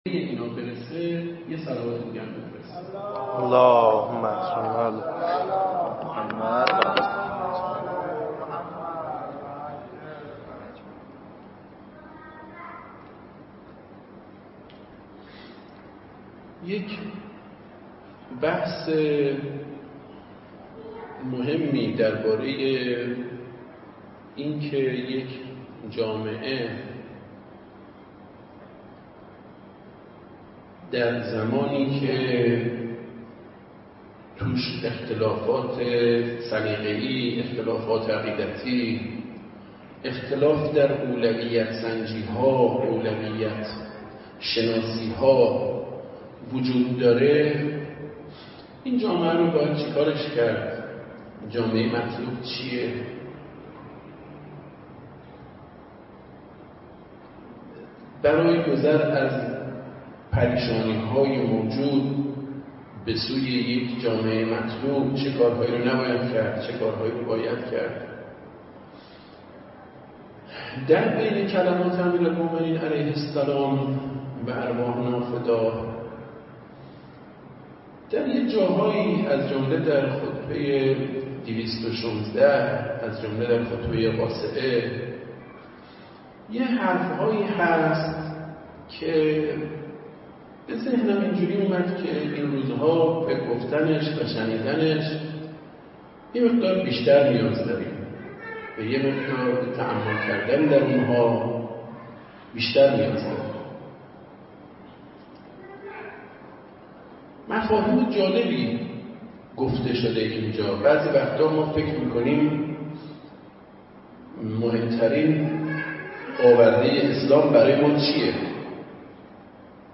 هیئت مدرسه